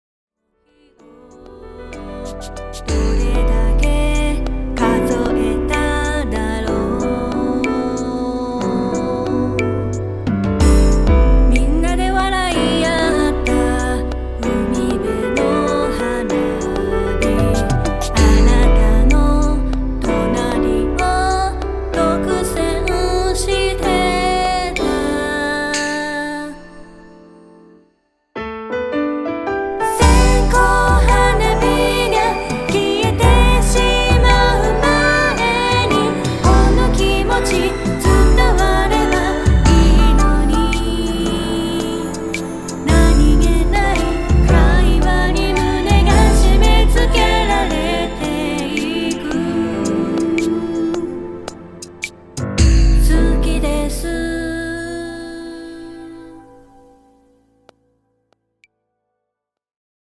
夏の残影を偲ばせるエレクトロ・ファンタジア。 共鳴する音空間の中に、貴方の夏影を呼び起こします。